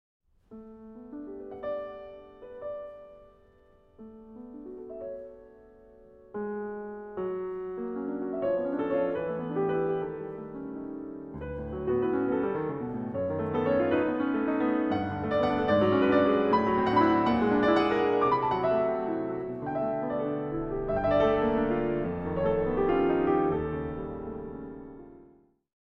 Works for piano